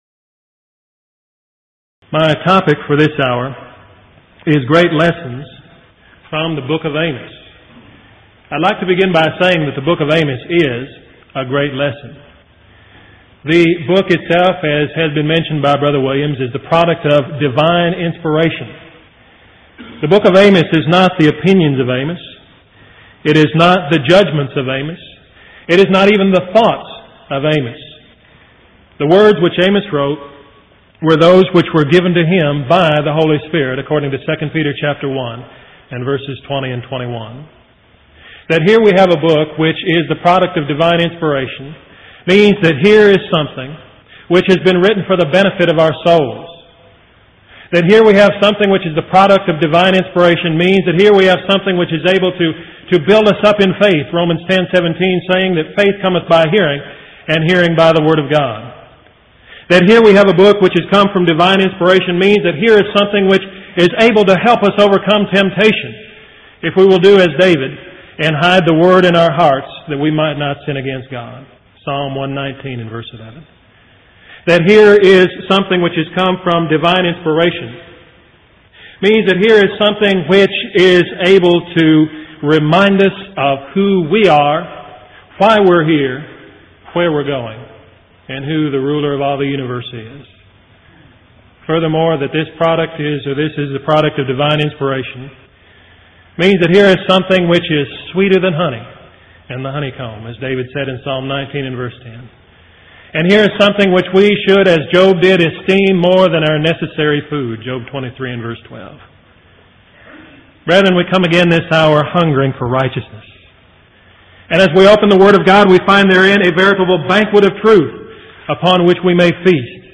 Event: 1990 Power Lectures
lecture